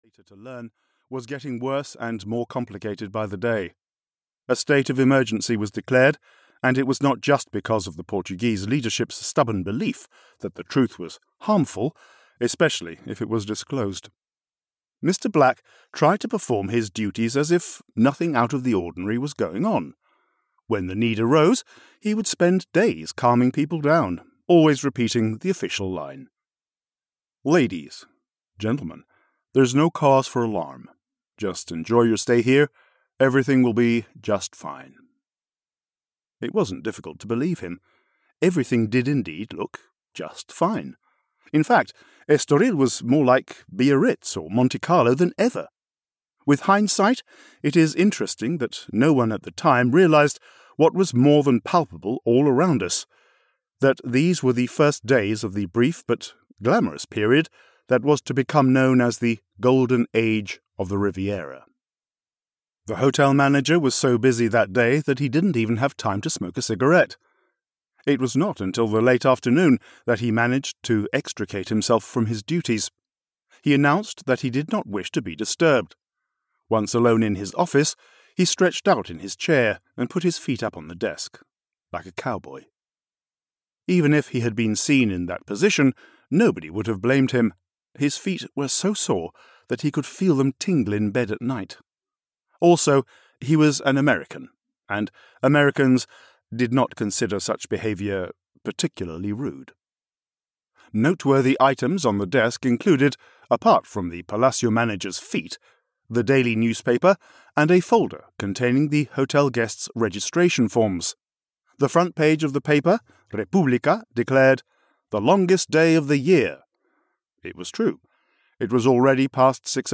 Audio knihaEstoril (EN)
Ukázka z knihy